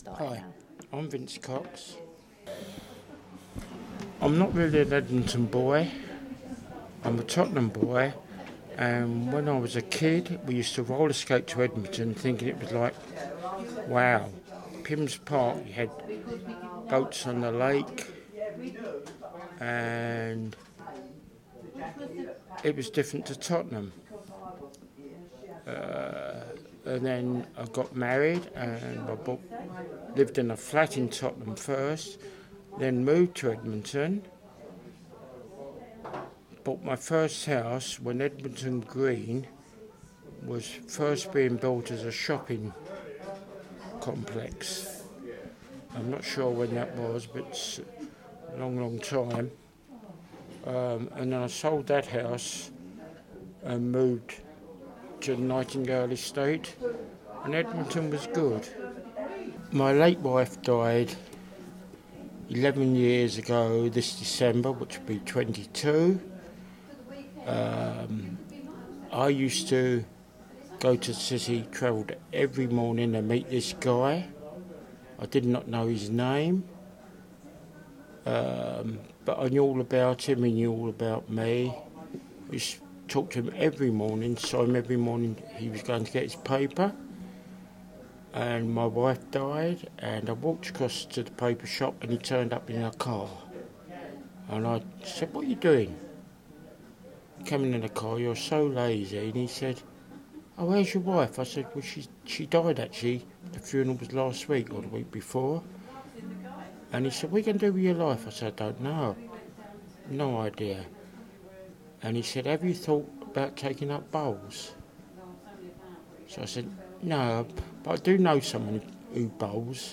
In Spring 2022 the artists 34Bus Collective worked with members of the club and the local community to collect oral stories from members.